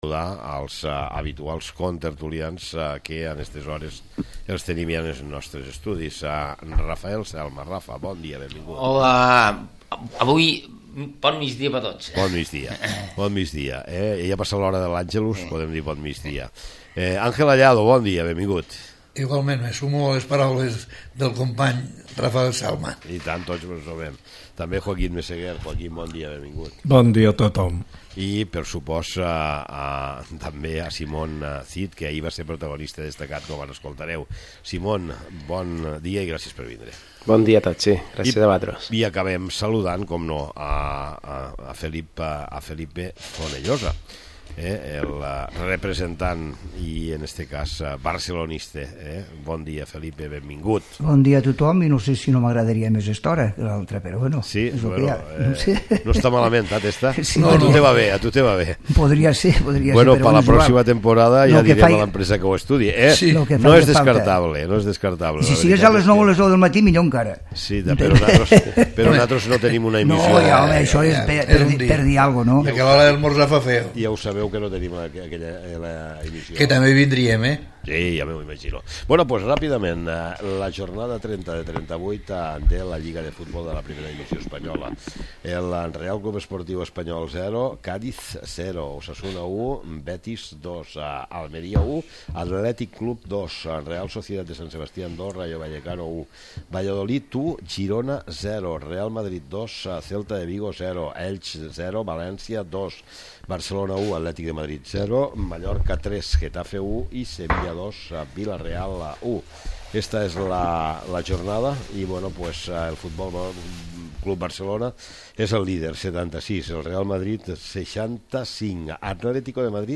tertúlia de futbol https